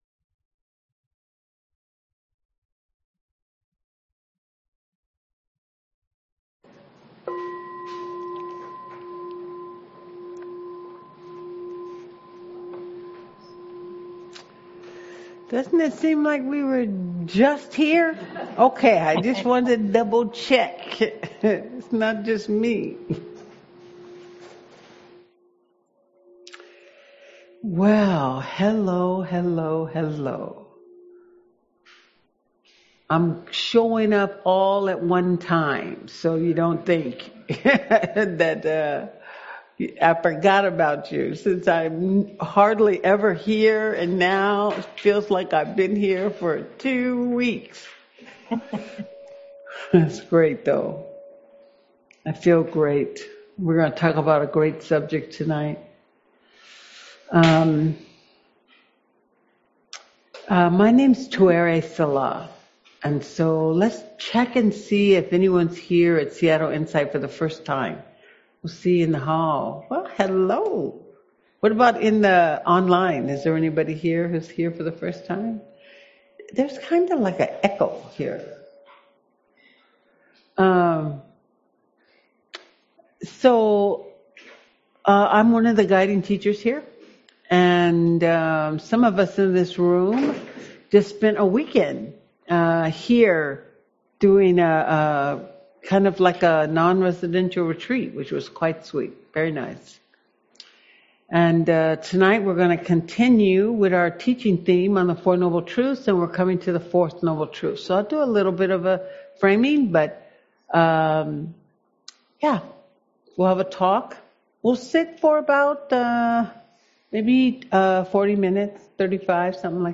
Audio recordings of Buddhist teachings and discussions with local and visiting teachers of the Dhamma.